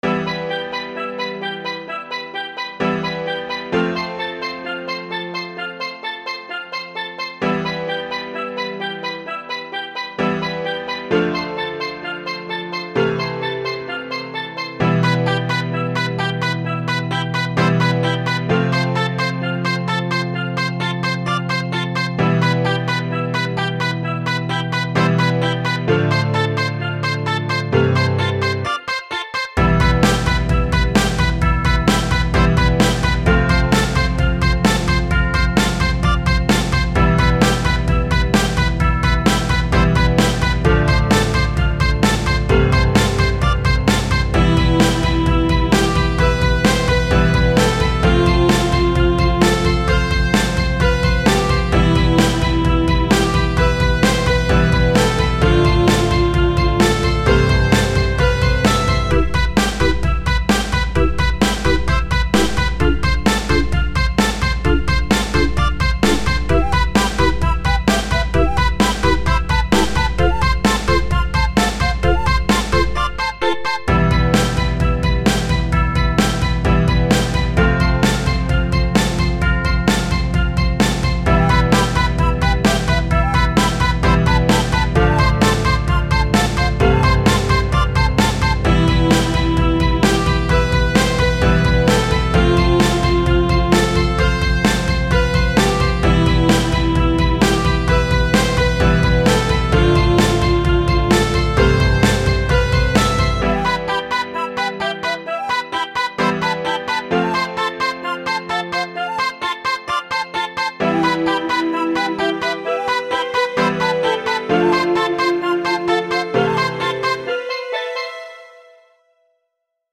VGM steel drum
loopable